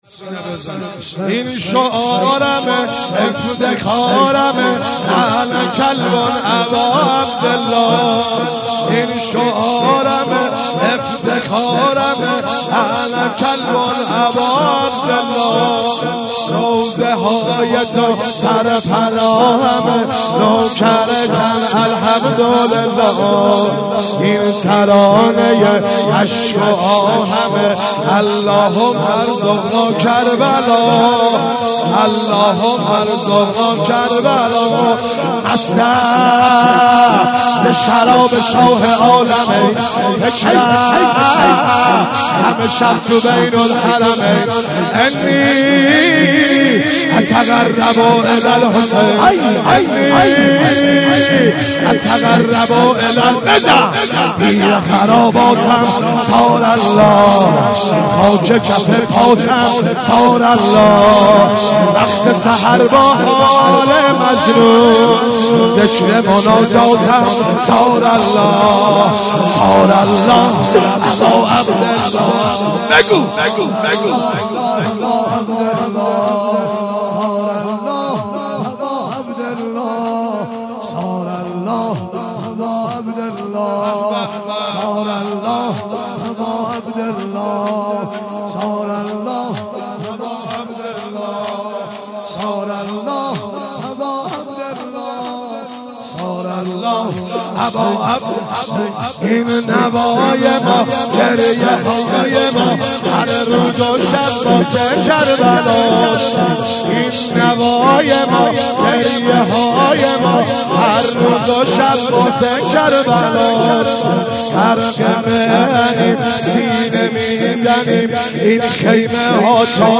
5- این شعارمه افتخارمه - شور